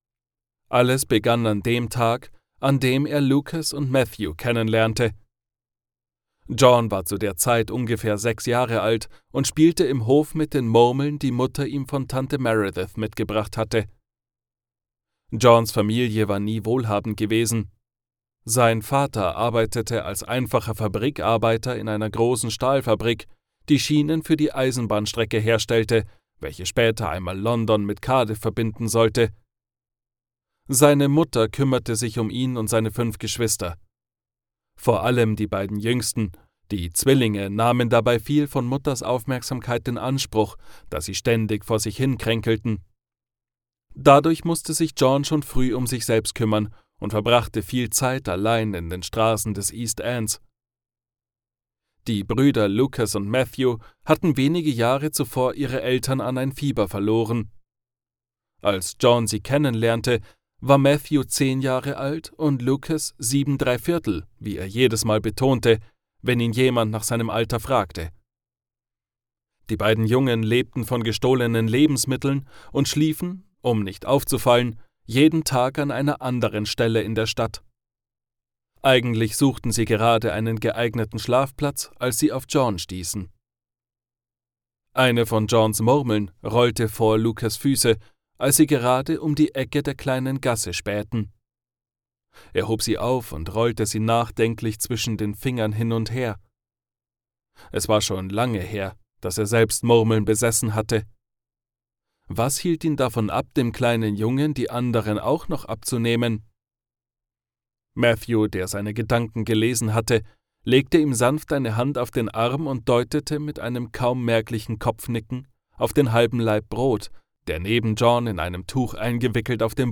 Hörbuch | Historisches